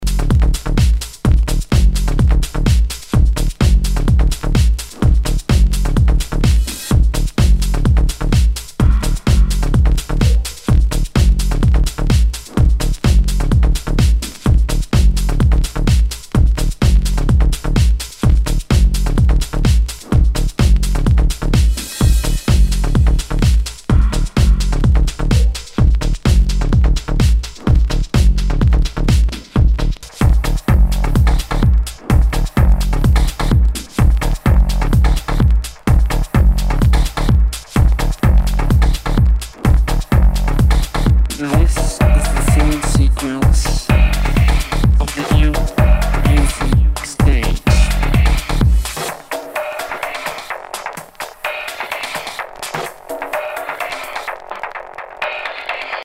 HOUSE/TECHNO/ELECTRO
ナイス！プログレッシブ・ハウス！
全体にチリノイズが入ります